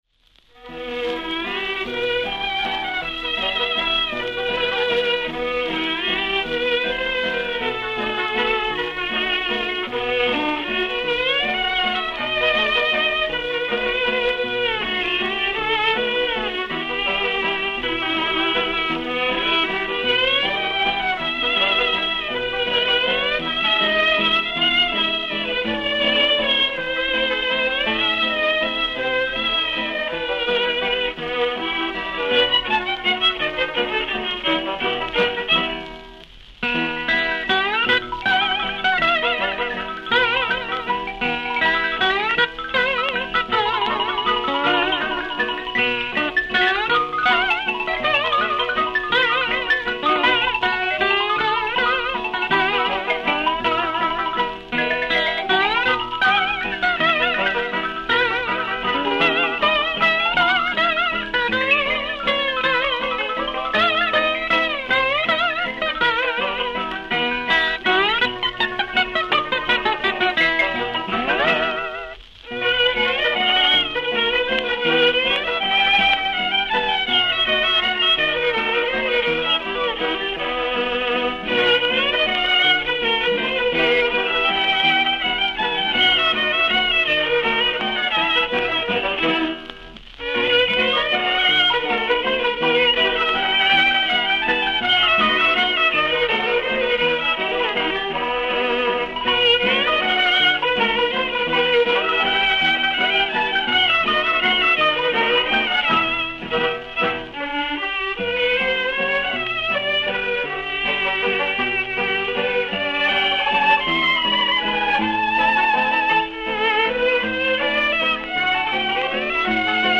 hawaiin Guitar
Каталожная категория: Танцевальный оркестр |
Жанр: Вальс
Вид аккомпанемента: Оркестр
Место записи: Нью-Йорк |